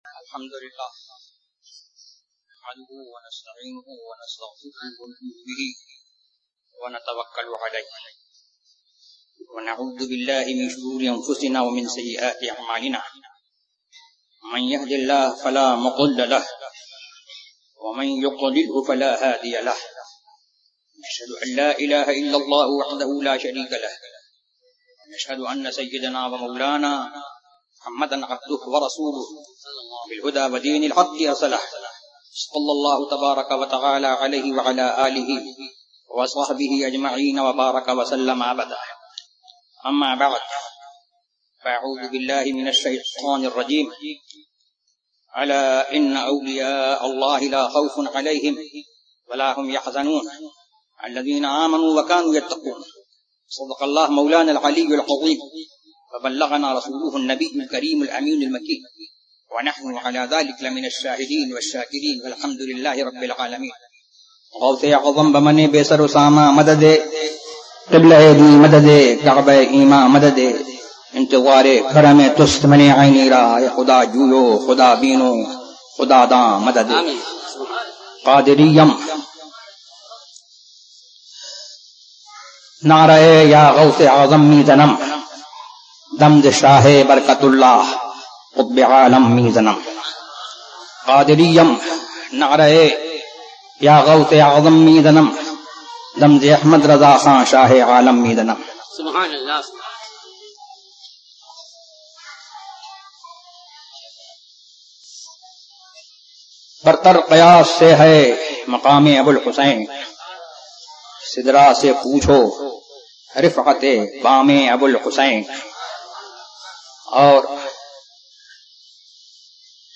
عرسِ نوری برکاتی ZiaeTaiba Audio میڈیا کی معلومات نام عرسِ نوری برکاتی موضوع تقاریر آواز تاج الشریعہ مفتی اختر رضا خان ازہری زبان اُردو کل نتائج 872 قسم آڈیو ڈاؤن لوڈ MP 3 ڈاؤن لوڈ MP 4 متعلقہ تجویزوآراء